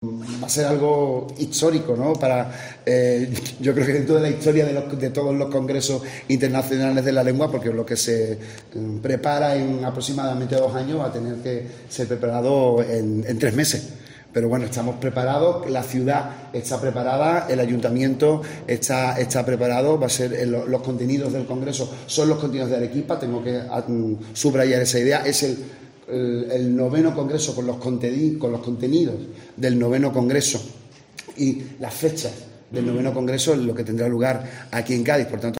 El alcalde de Cádiz confirma que la ciudad acogerá el Congreso de la Lengua